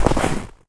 STEPS Snow, Run 17.wav